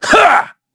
Shakmeh-Vox_Attack3_kr.wav